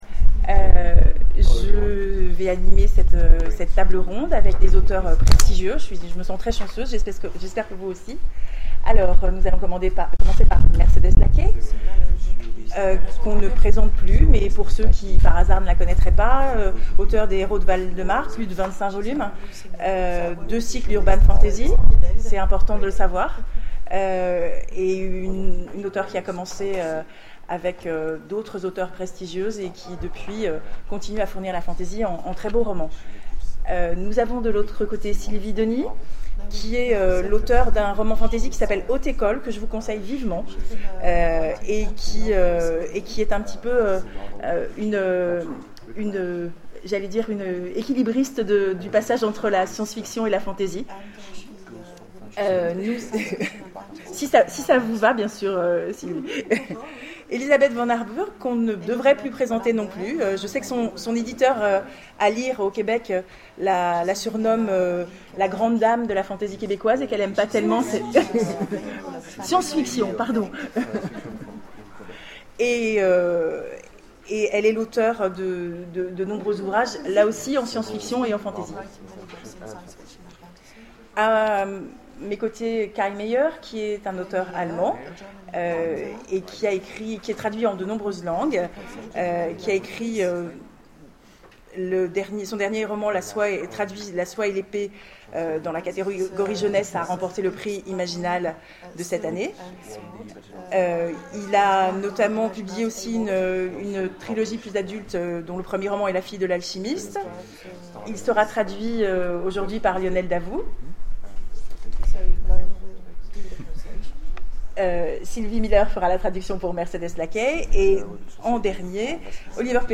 Imaginales 2012 : Conférence La fantasy, une littérature de la nostalgie ?